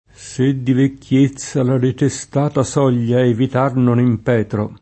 imp$tro] — es.: Sì dolcemente che mercé m’impetre [S& ddol©em%nte ke mmer©% mm imp$tre] (Petrarca); se di vecchiezza La detestata soglia Evitar non impetro [